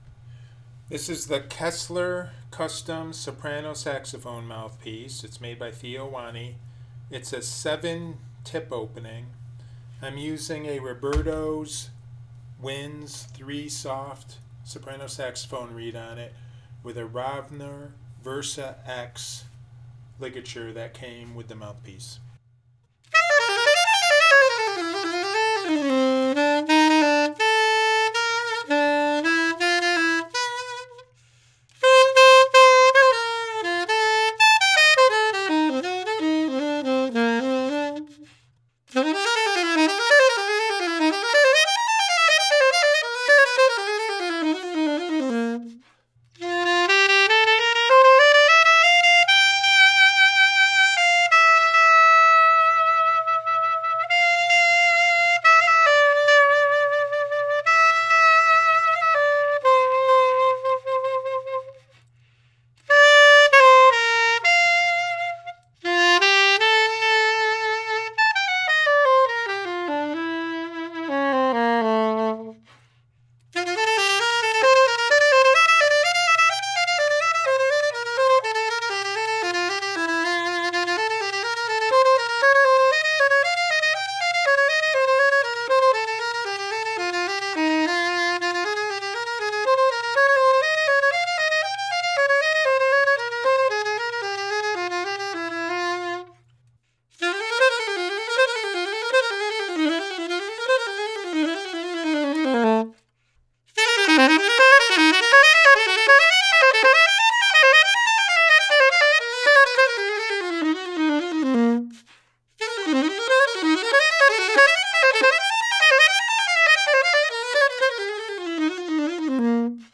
The articulation was clean and crisp and the Kessler Custom 7 soprano saxophone mouthpiece performed well on fast jazz lines while applying articulation.
It is a “dry” recording meaning no effects have been added at all.
In my opinion, the Kessler Custom 7 soprano saxophone mouthpiece is a fabulous budget friendly soprano saxophone mouthpiece for those looking for a large chambered soprano sax mouthpiece that delivers a medium bright and powerful room-filling tone that sounds big, full and fat sounding.
Kessler Custom 7 Soprano Saxophone Mouthpiece by Theo Wanne – Same Clip as Above with No Reverb Added-Robertos Winds 3 Soft Soprano Saxophone Reed – Rovner Versa-X Ligature